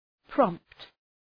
Προφορά
{prɒmpt}